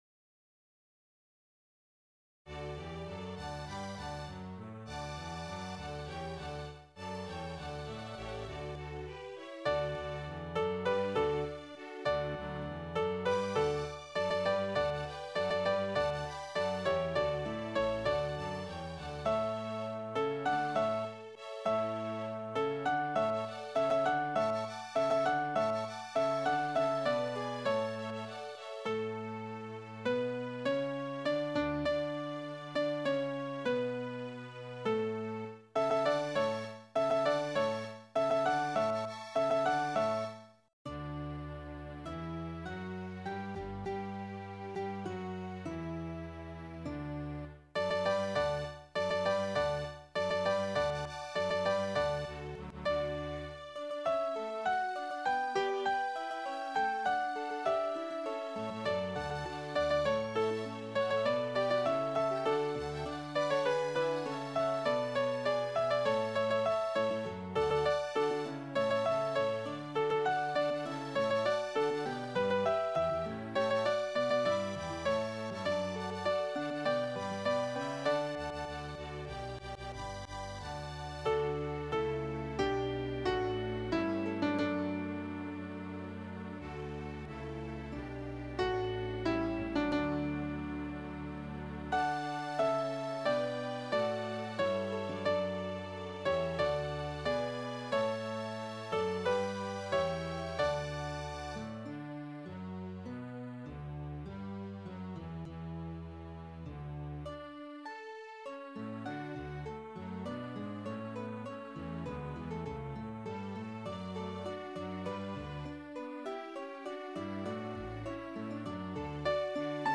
Sopr.